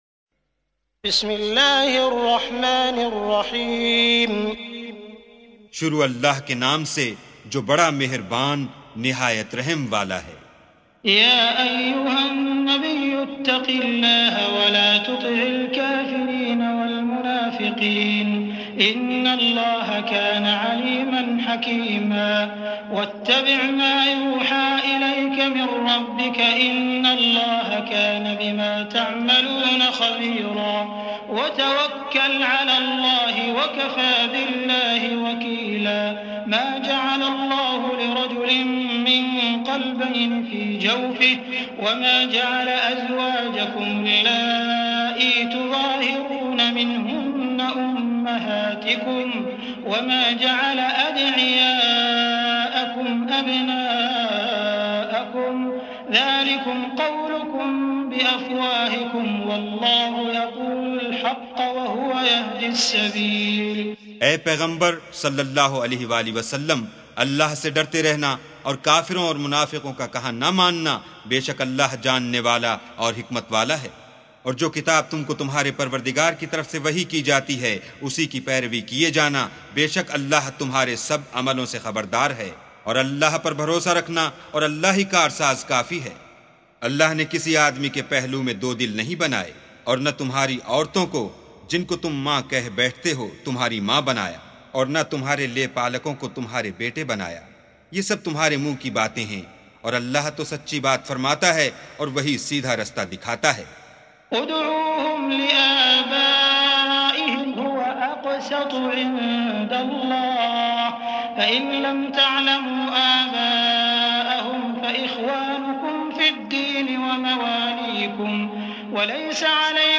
سُورَةُ الأَحۡزَابِ بصوت الشيخ السديس والشريم مترجم إلى الاردو